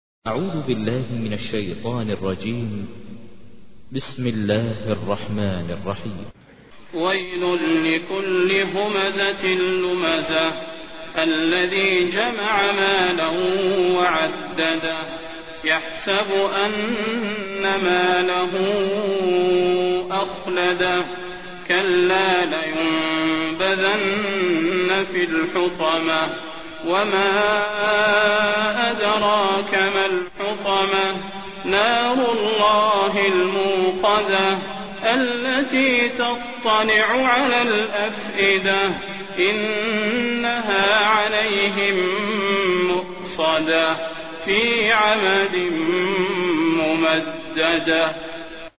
تحميل : 104. سورة الهمزة / القارئ ماهر المعيقلي / القرآن الكريم / موقع يا حسين